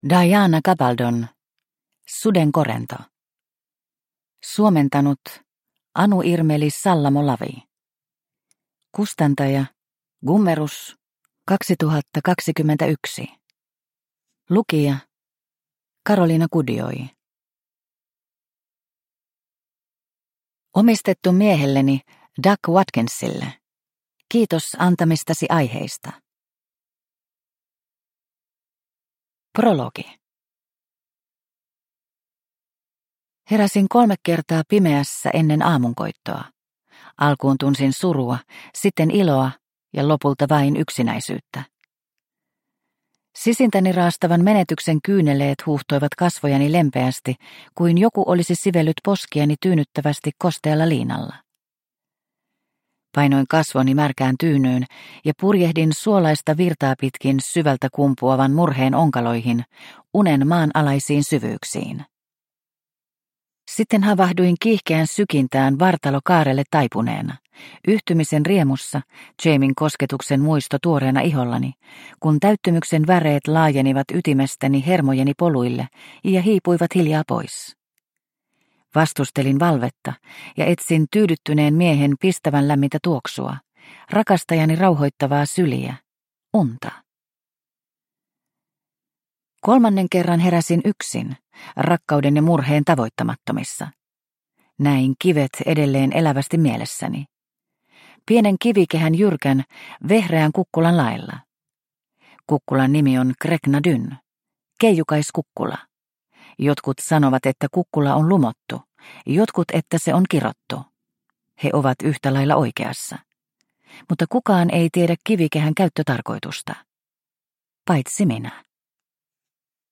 Sudenkorento – Ljudbok – Laddas ner